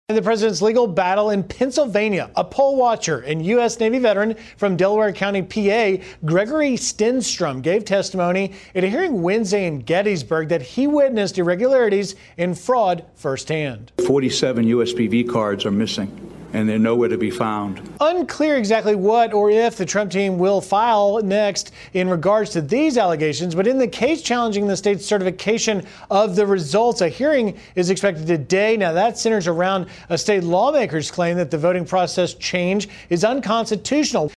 reports: